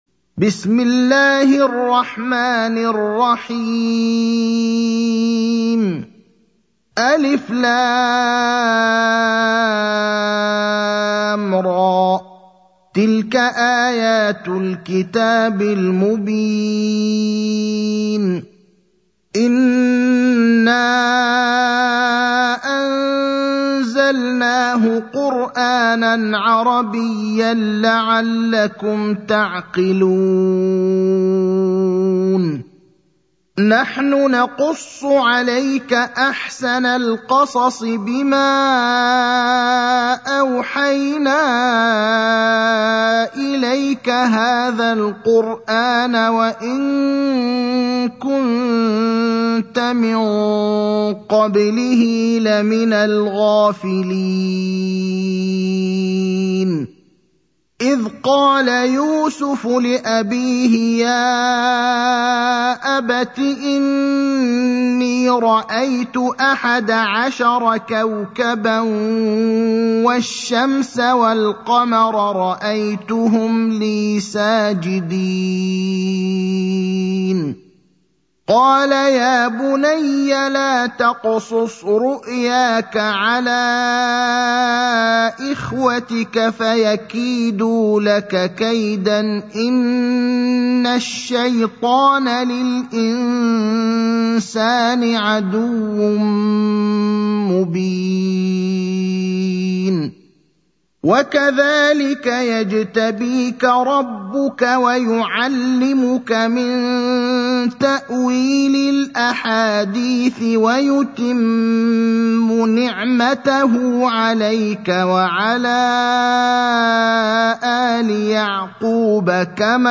المكان: المسجد النبوي الشيخ: فضيلة الشيخ إبراهيم الأخضر فضيلة الشيخ إبراهيم الأخضر يوسف (12)